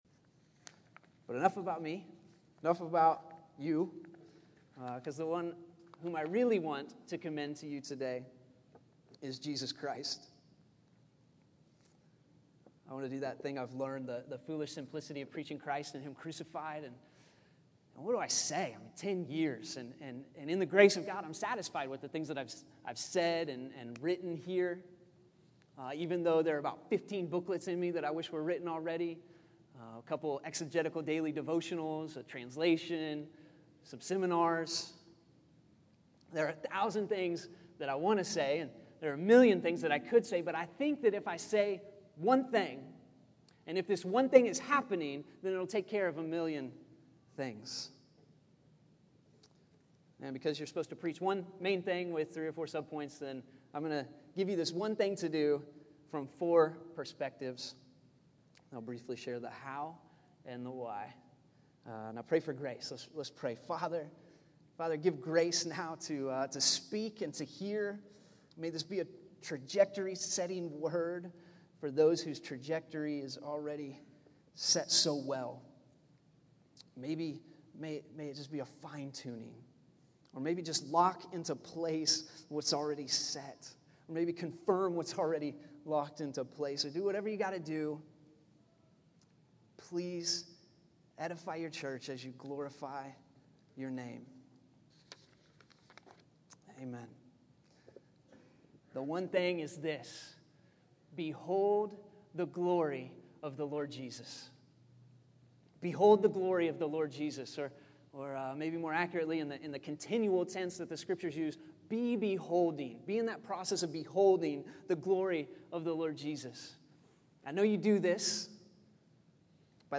Behold the Glory of the Lord Jesus August 18, 2013 Category: Sermons | Location: El Dorado Back to the Resource Library This is the one thing, from four perspectives, in desperate prayer for the Spirit of revelation.